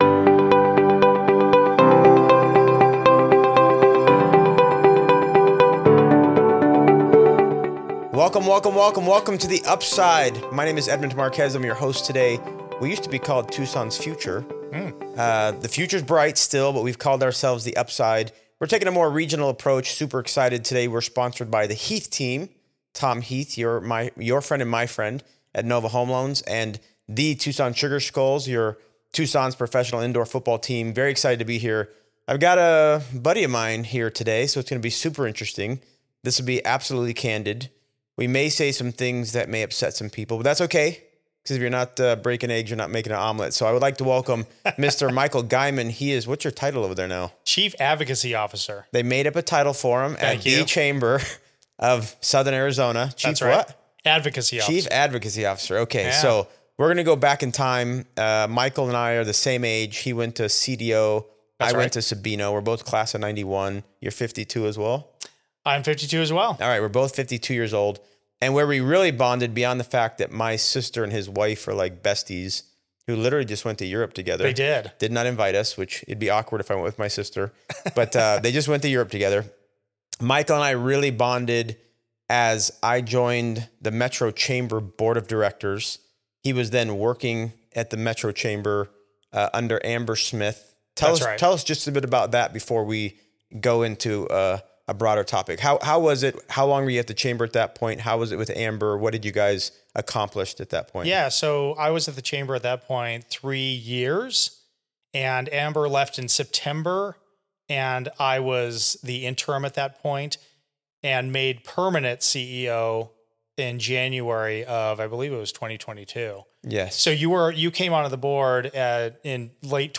A candid conversation about Tucson jobs, leadership, business climate, and what it will take to move Southern Arizona forward.